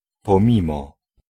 Ääntäminen
IPA : /dɪˈspaɪt/